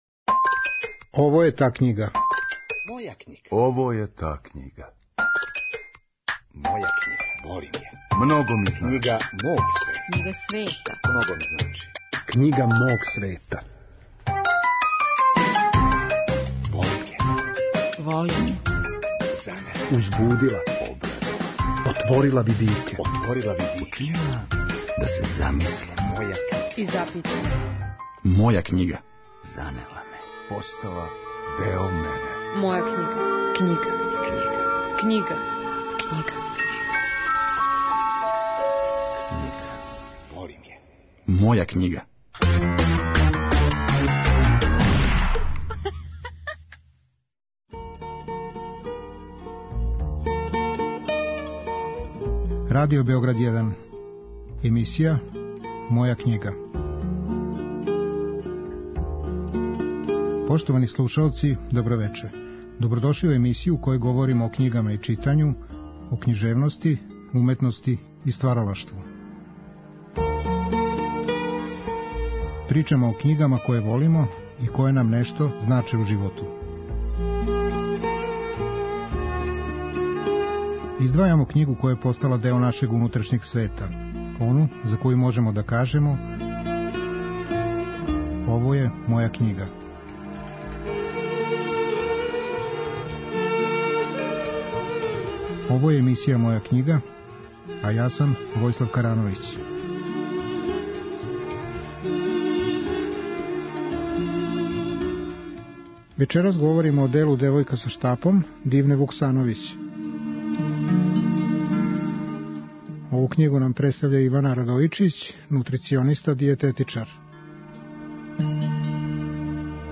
Емисија о књигама и читању, о књижевности, уметности и стваралаштву.
Наша саговорница говори о томе шта јој роман ''Девојка са штапом'' Дивне Вуксановић значи, како га је открила и доживела, говори о његовој слојевитости, о ликовима и атмосфери, као и о граници између живота и смрти која се може открити у структури овог дела.